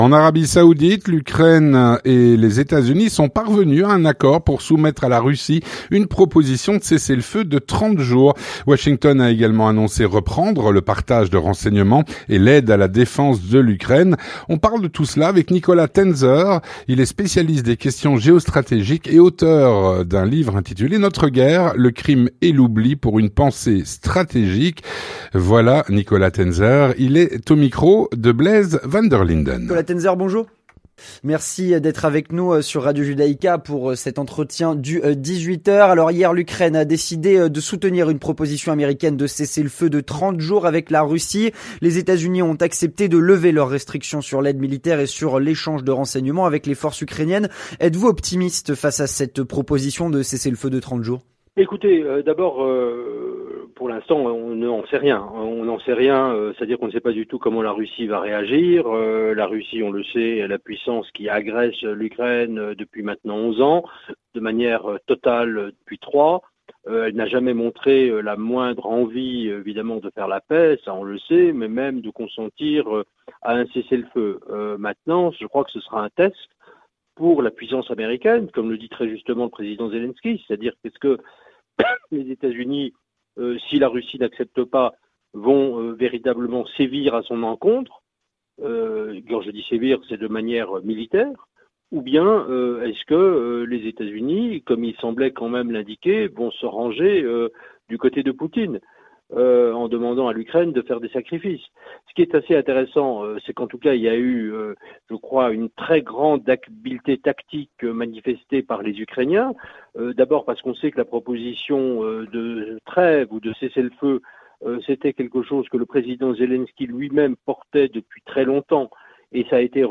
L'entretien du 18H - En Arabie saoudite, l’Ukraine et les États-Unis sont parvenus à un accord pour une proposition de cessez-le-feu de 30 jours.